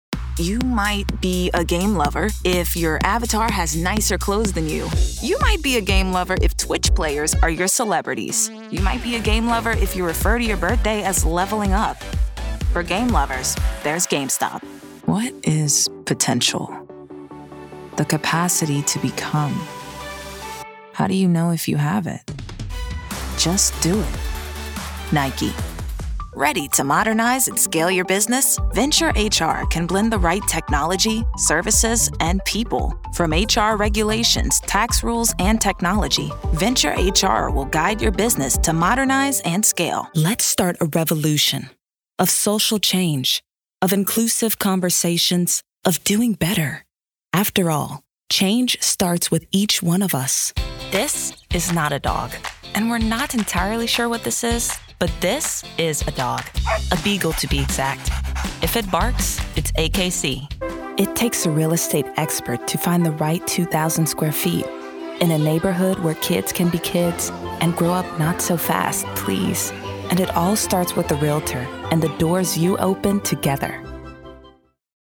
Broadcast Quality Home Studio
Commercial Demo